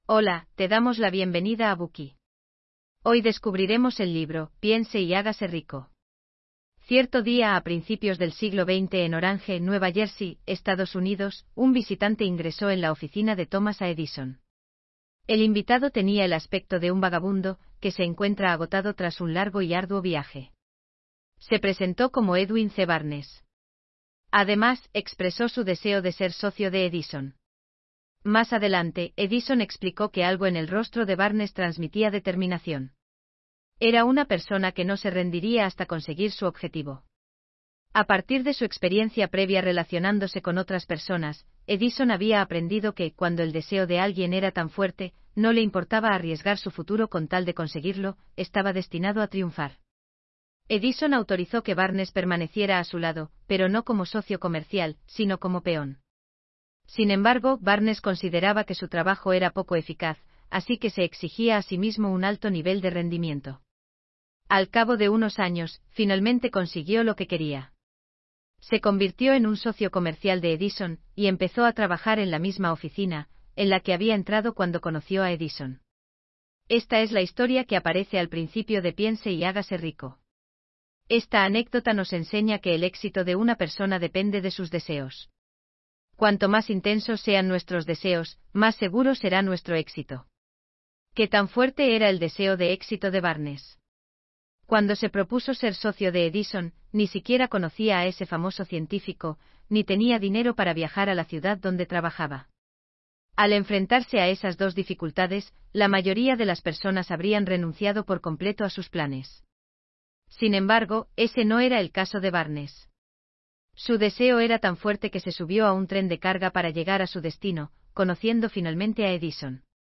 "Piense y hágase rico", de Napoleon Hill, es un libro intemporal de autoayuda que enseña a los lectores los principios del éxito. La versión en audiolibro condensa este clásico en aspectos clave, destacando conceptos importantes para ayudar a los oyentes a adoptar una mentalidad positiva y alcanzar sus objetivos. 1.